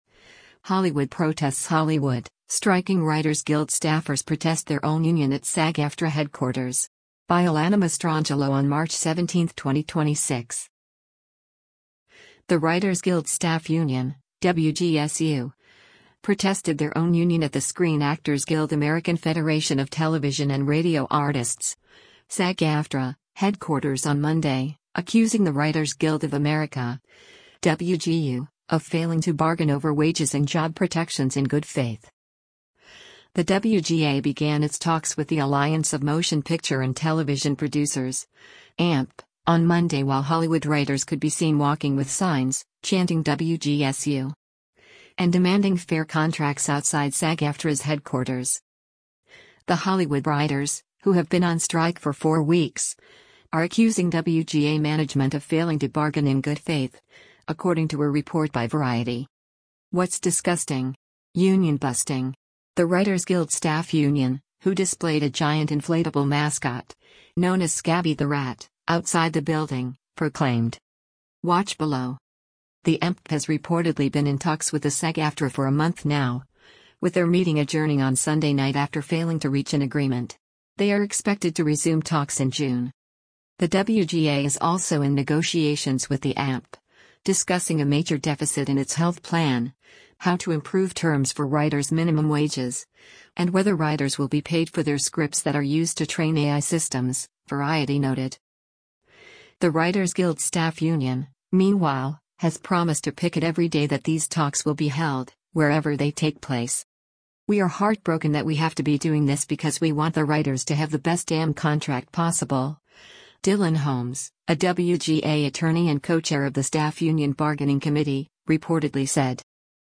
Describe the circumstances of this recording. The WGA began its talks with the Alliance of Motion Picture and Television Producers (AMPTP) on Monday while Hollywood writers could be seen walking with signs, chanting “WGSU!” and demanding “fair contracts” outside SAG-AFTRA’s headquarters.